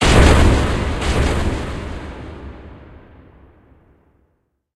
Cri de Shifours Gigamax Style Poing Final dans Pokémon HOME.
Cri_0892_Gigamax_Poing_Final_HOME.ogg